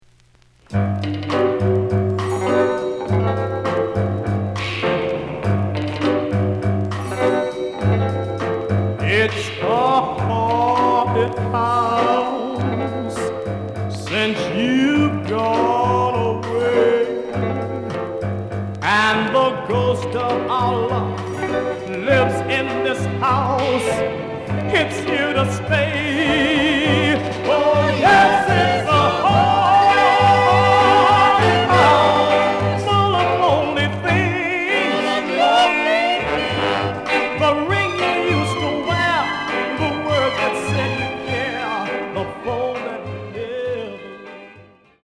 The listen sample is recorded from the actual item.
●Format: 7 inch
●Genre: Soul, 60's Soul